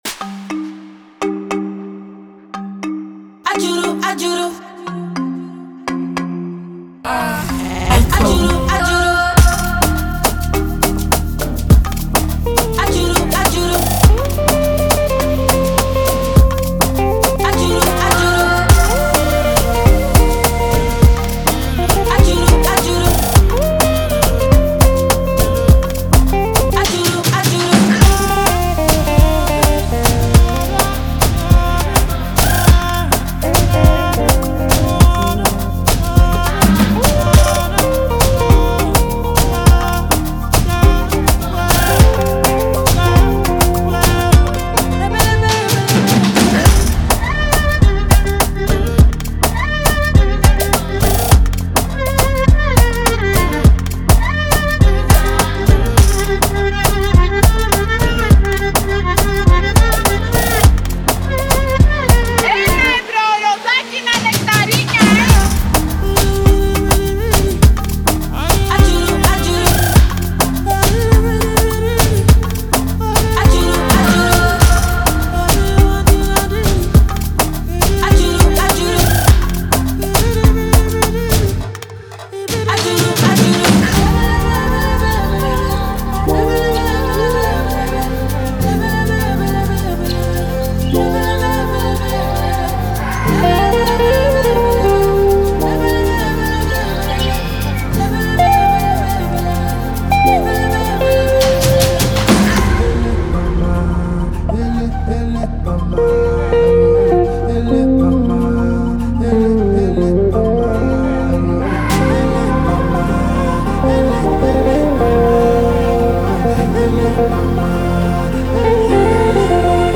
Afro-pop
a new type of Afrobeats infused with world sounds